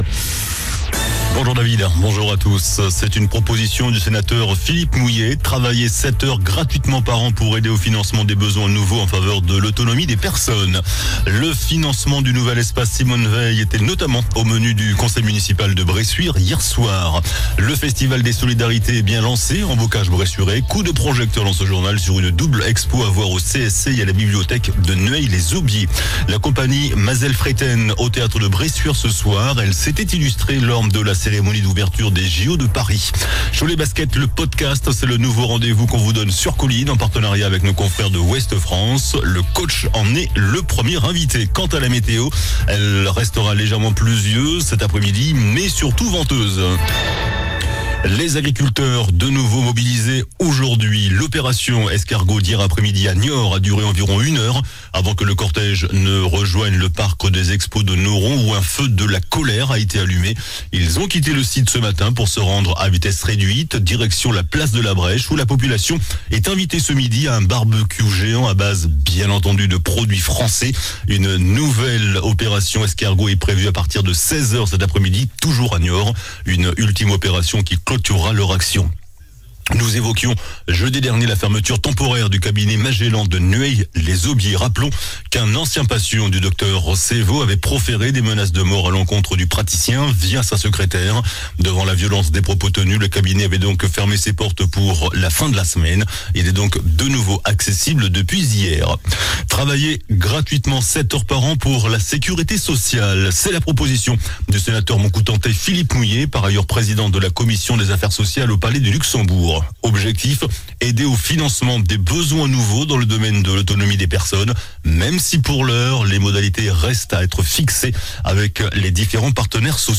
JOURNAL DU MARDI 19 NOVEMBRE ( MIDI )